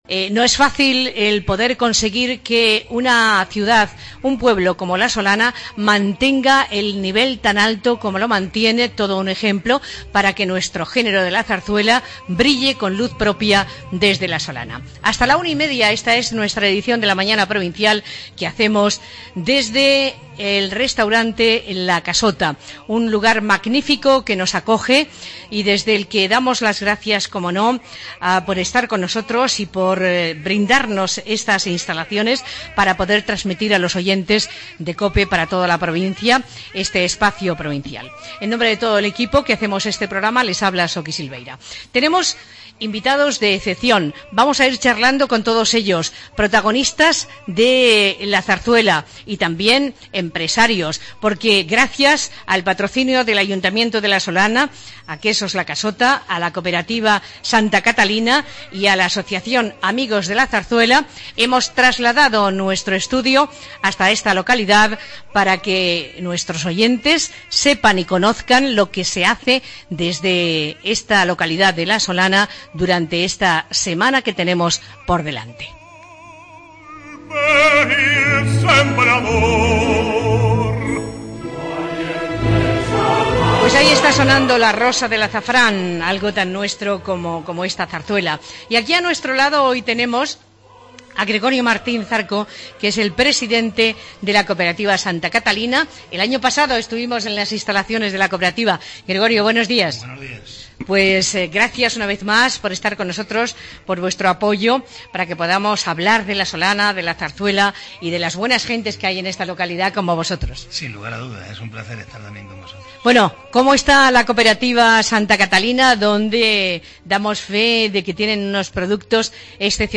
Esta noche comienza la XXII Semana de la Zarzuela de La Solana, declarada de Interés Turístico Nacional. Y hoy, LA MAÑANA DE COPE CIUDAD REAL se ha emitido desde el Hotel La Casota de La Solana, en un programa centrado en este gran evento que convierte a la localidad como la capital de la zarzuela. En esta primera parte han estado con nosotros algunos de los protagonistas de esta semana, entre ellos, el gran actor José Sacristán.